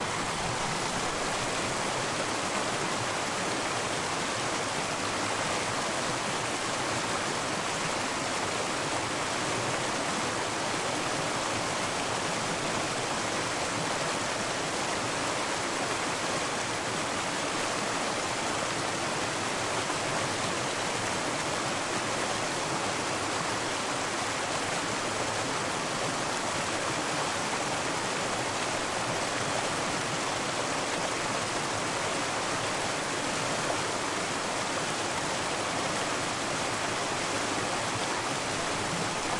Nature » Khasaut river
描述：Mountains river Khasaut, KarachayCherkessia, Russia
标签： creek river stream water flow rustling rustle mountains
声道立体声